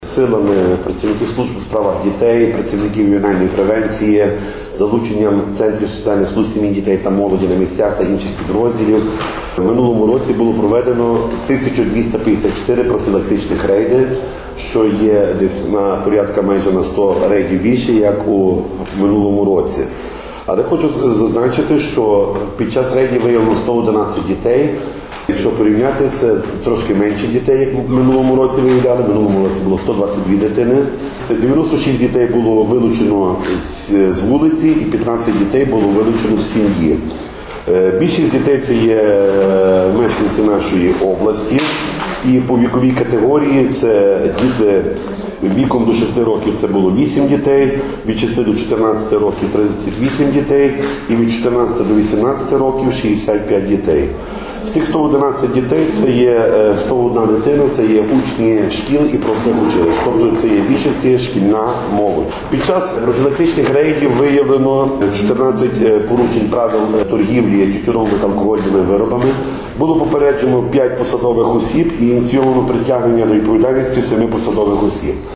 Упродовж 2018 року працівники служб у справах дітей спільно з представниками органів внутрішніх справ та соціальних служб провели 1254 профілактичних рейди і виявили 111 дітей. Про це повідомили сьогодні, 18 січня, під час брифінгу.